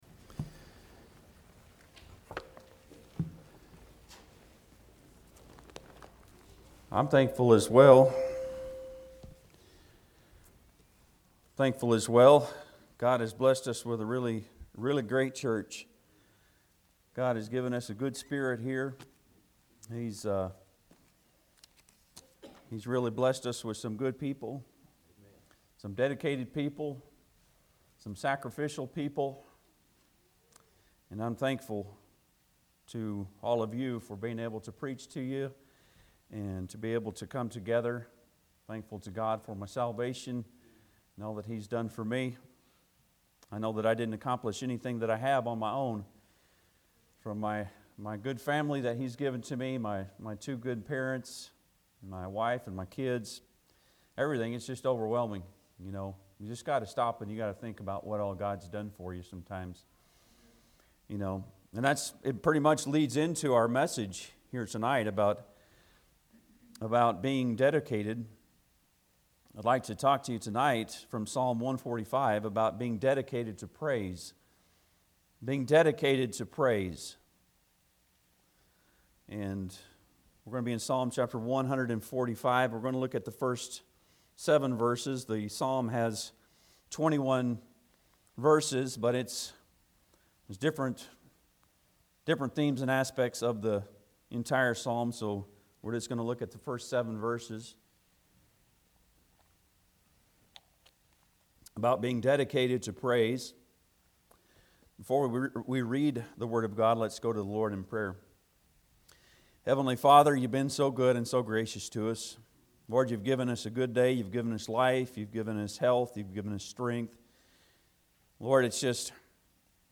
Psalm 145:1-7 Service Type: Sunday pm Bible Text